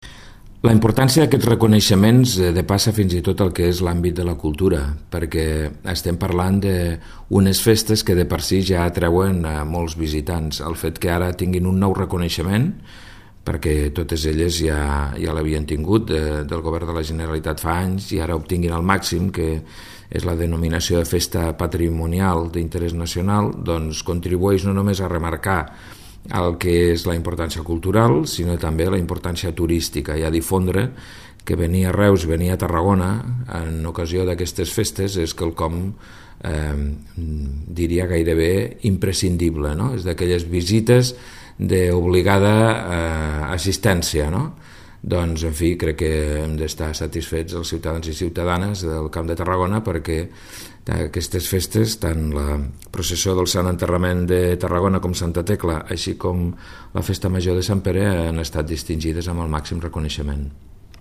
Tall de veu del delegat sobre les festes